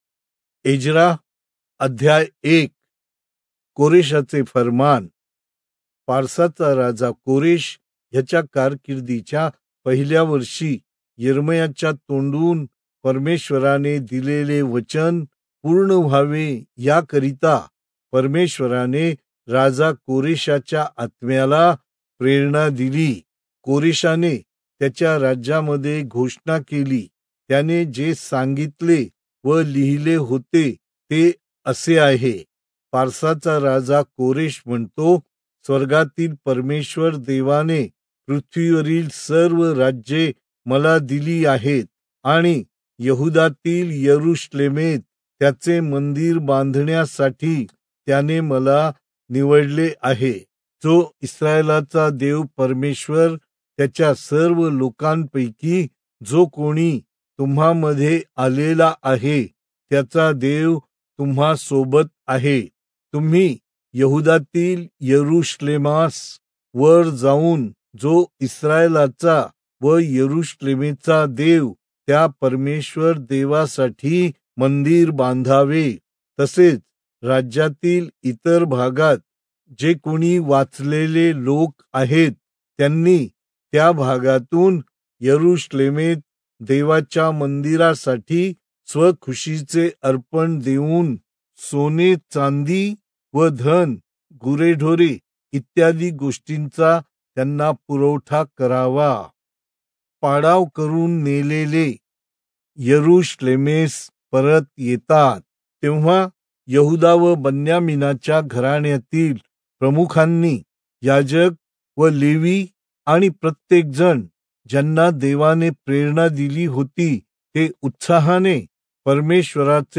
Marathi Audio Bible - Ezra 10 in Irvmr bible version